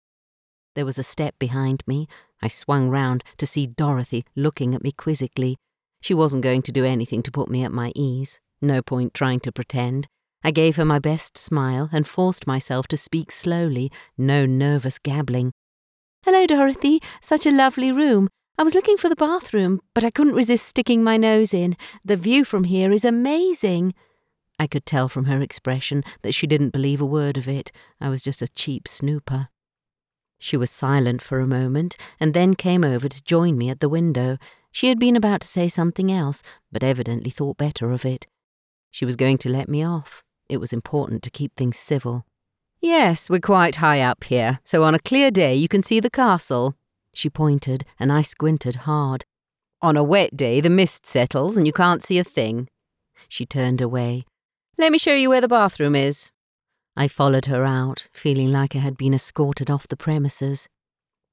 Livres audio
Mon accent anglais neutre est particulièrement adapté aux marchés internationaux.
Ma voix est naturelle et chaleureuse, tout en étant résonnante et autoritaire.
Micro Audio Technica AT2020